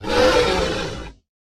Sound / Minecraft / mob / horse / zombie / angry.ogg
angry.ogg